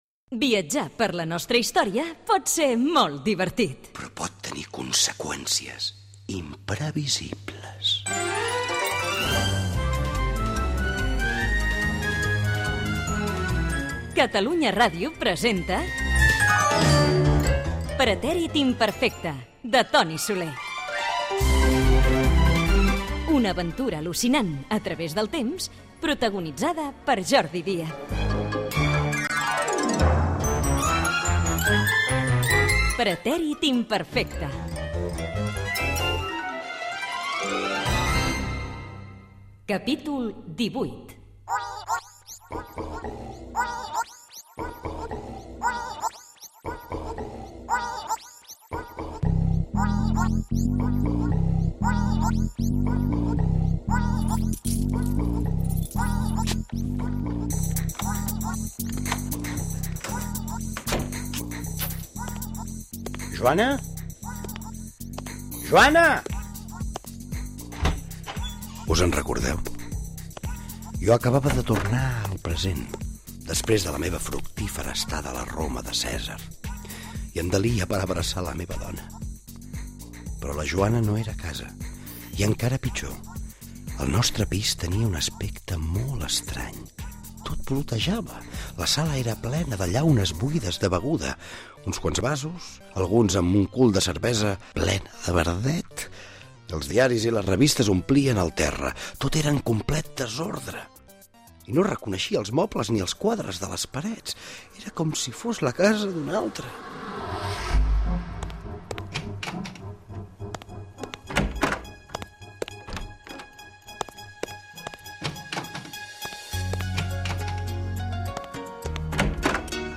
Capítol 18. Careta d'entrada, el personatge no troba a la Joana i careta de sortida amb l'equip